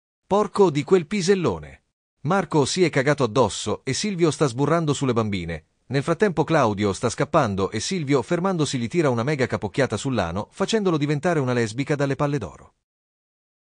porco di quel pisellone 2 Meme Sound Effect
This sound is perfect for adding humor, surprise, or dramatic timing to your content.